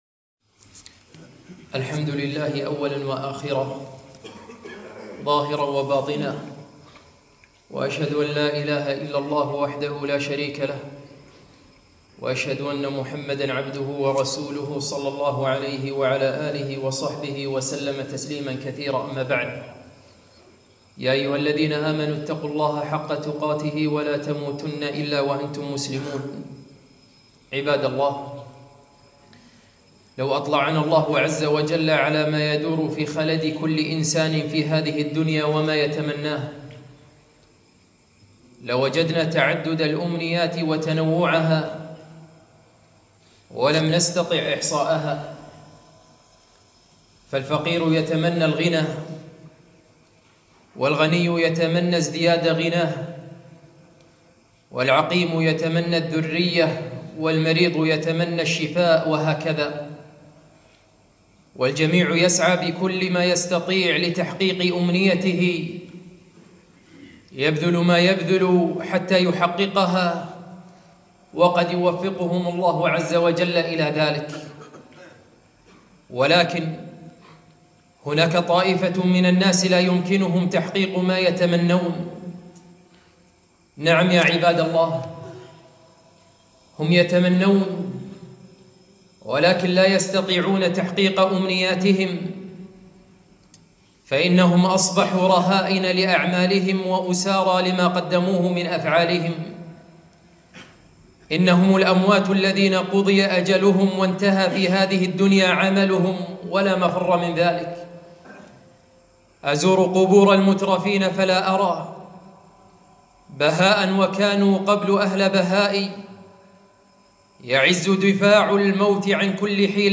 خطبة - أمـنيـات